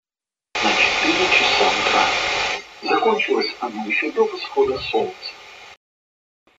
Тогда теряется достоверность, динамика намного меньше стает + слышу работу встроенного шумодава.